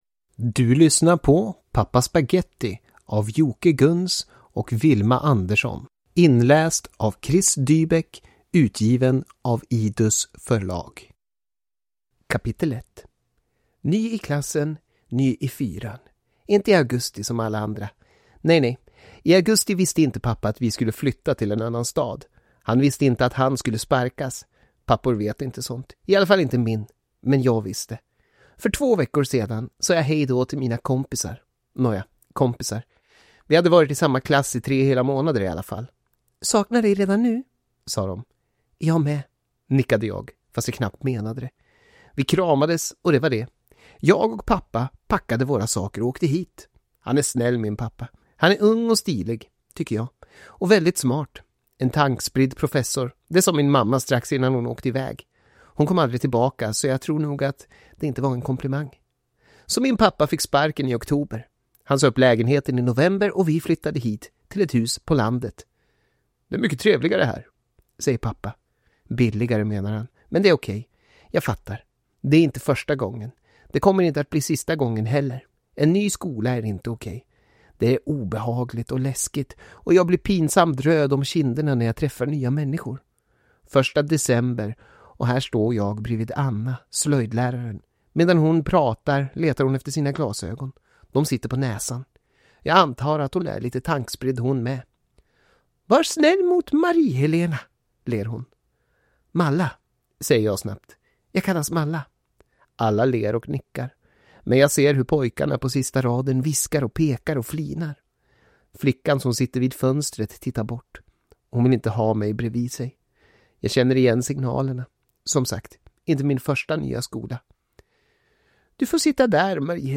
Pappa Spaghetti – Ljudbok